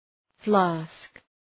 Προφορά
{flæsk}